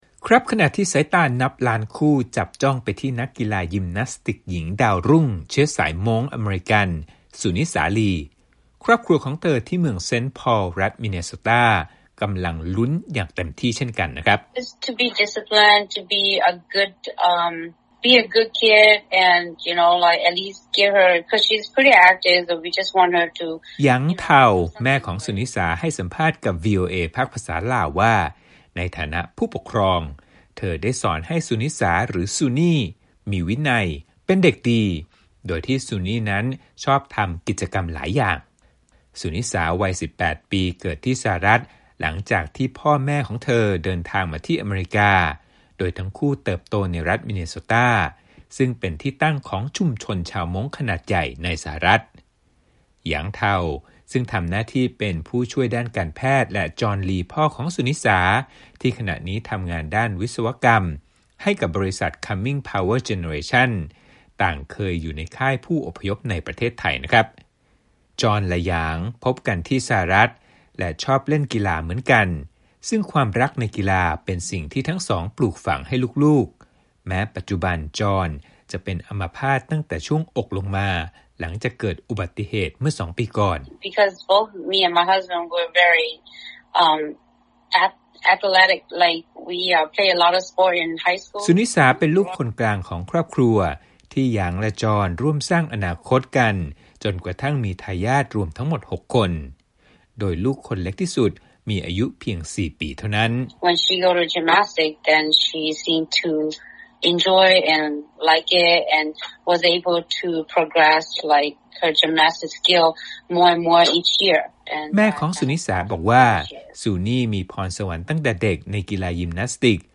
สัมภาษณ์พิเศษ: ลมใต้ปีกของ “สุนิสา ลี” นักยิมฯ หญิงดาวรุ่งอเมริกันเชื้อสายม้ง